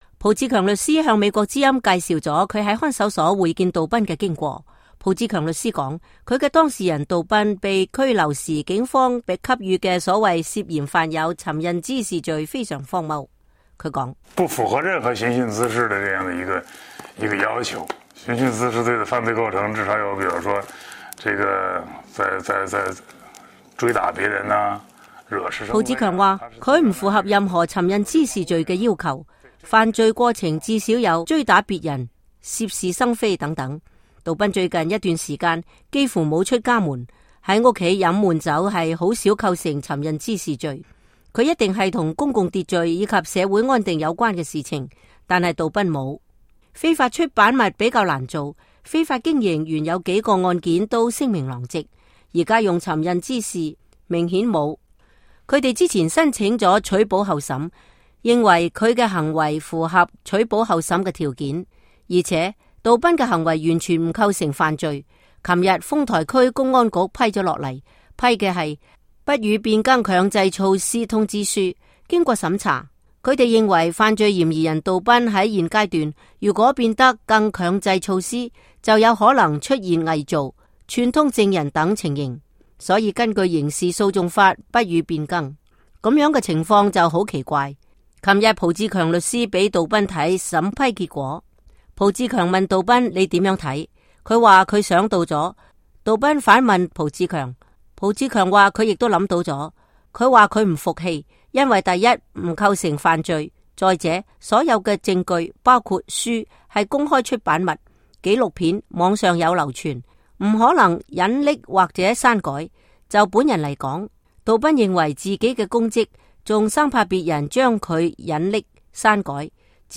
浦志強律師向美國之音講述了他在看守所會見杜斌的經過。